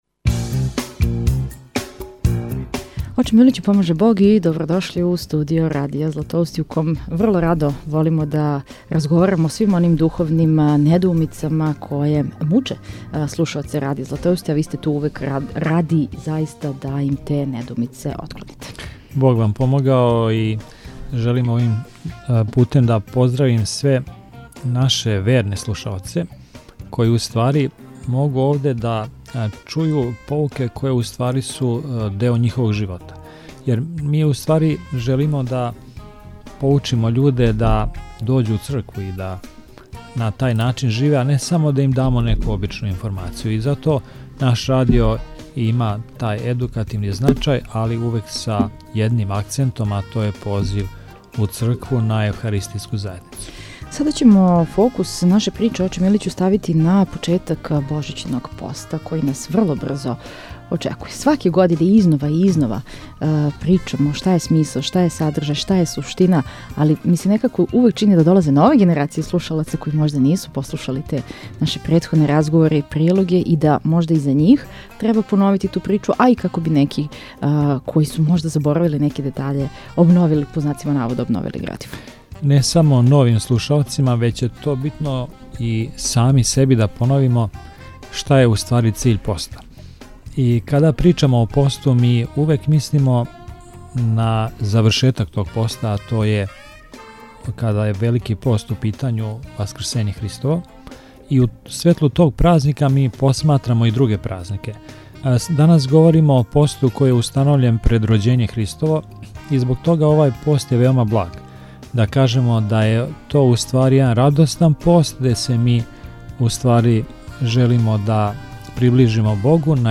разговарамо